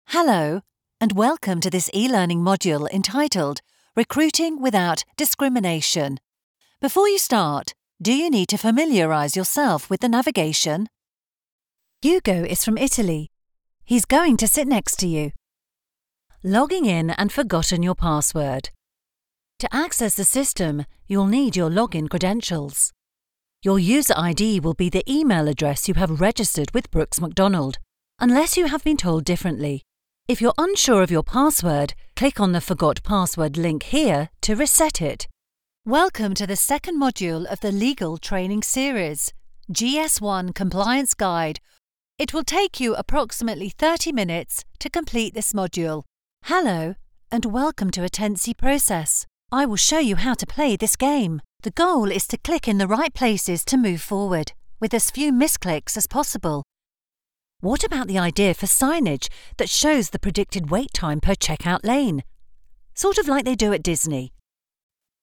Female
British English (Native)
Approachable, Bubbly, Confident, Conversational, Friendly, Natural, Smooth, Upbeat, Witty
CORPORATE REEL.mp3
Microphone: Rode NT1A